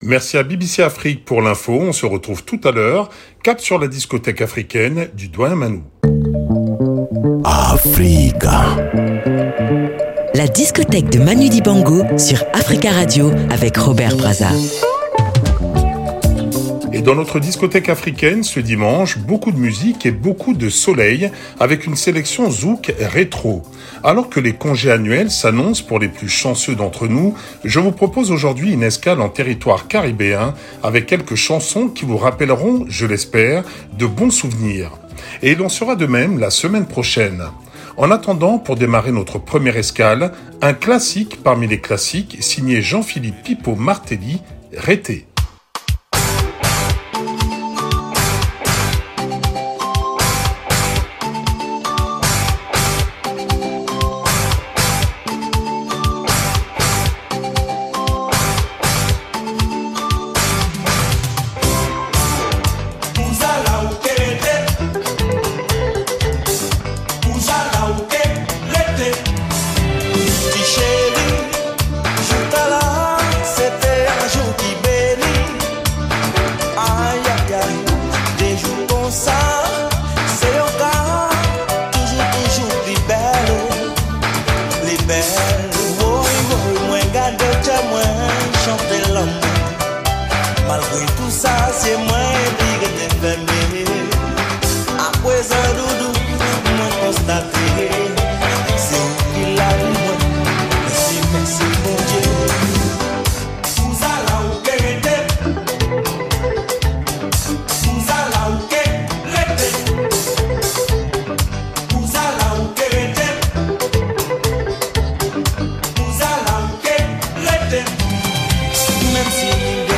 une sélection Zouk rétro